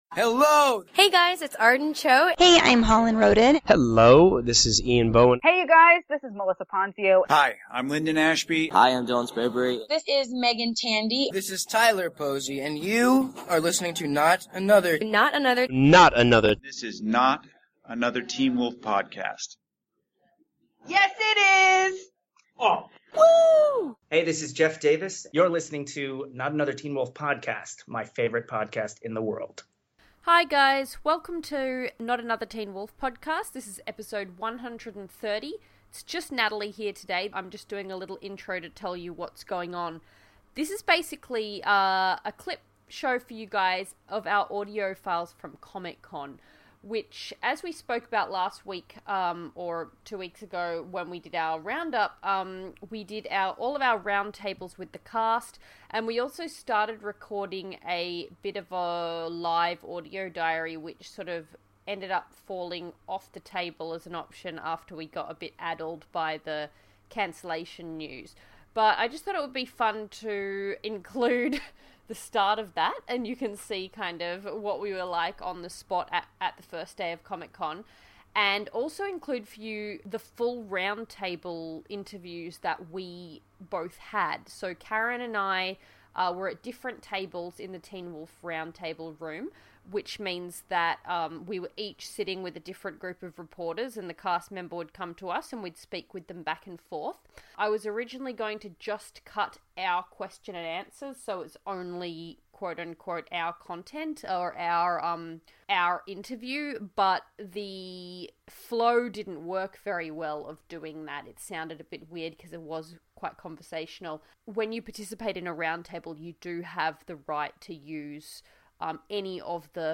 It's a very special clip show as we release our collection of SDCC interviews with the Teen Wolf cast and showrunner Jeff Davis.
- As promised, we're letting you take a listen to the raw files of our San Diego Comic-Con roundtable interviews with the cast of Teen Wolf.
- A warning - this is not smooth, easy-listening audio. There's a lot of noise pollution, but it's the real deal.